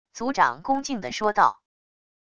族长恭敬的说道wav音频生成系统WAV Audio Player